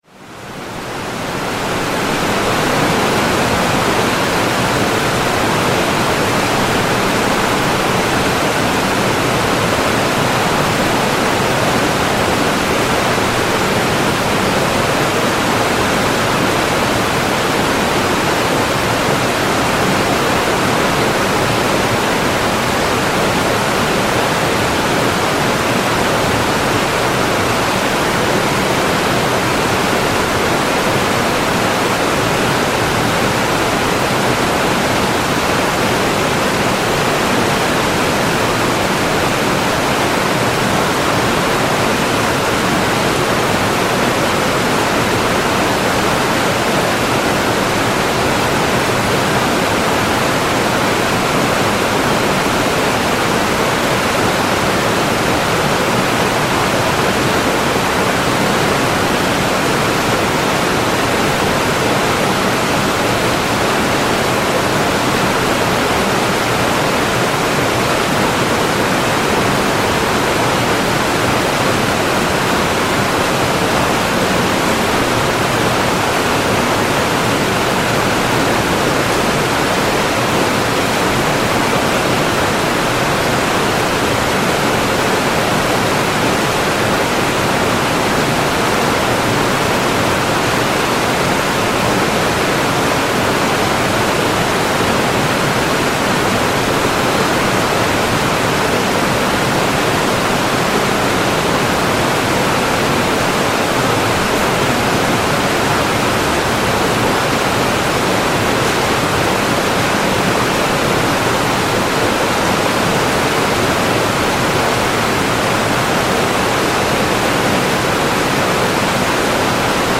Torrents of canyon water
Stones and plants overwhelmed by the flood of water, once they enter the canyon they create whirlpools, they hit the walls, digging into the rock and then flow downstream. The flow of water in narrow valleys like this is also characterized by the greatly amplified noise of flowing water, which does not happen in normal more open valleys, and can clearly be heard in this recording.